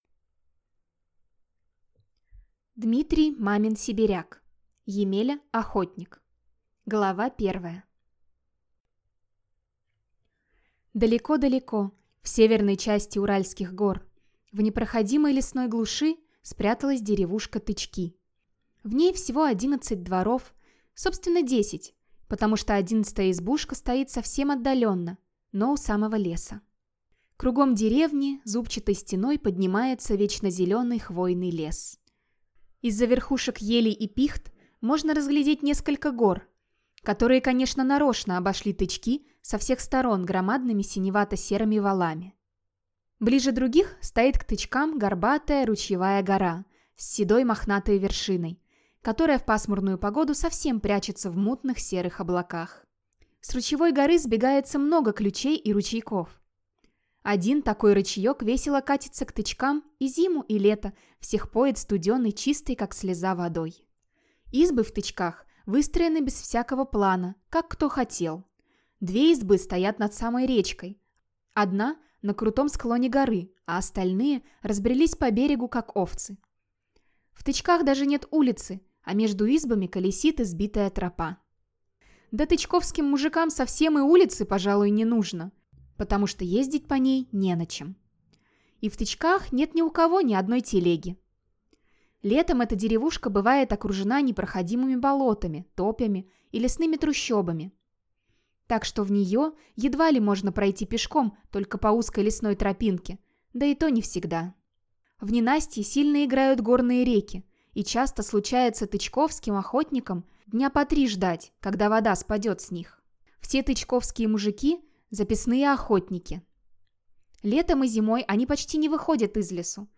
Аудиокнига Емеля-охотник | Библиотека аудиокниг
Прослушать и бесплатно скачать фрагмент аудиокниги